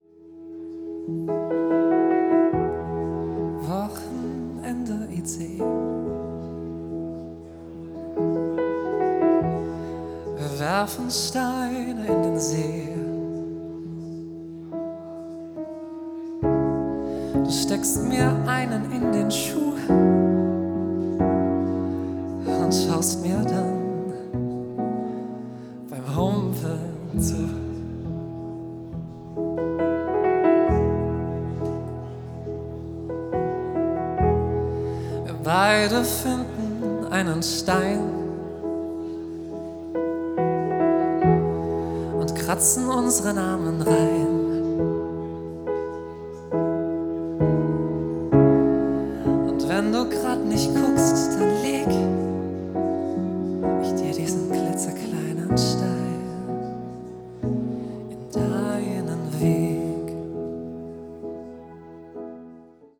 live von der celebrations Hochzeitsmesse Frankfurt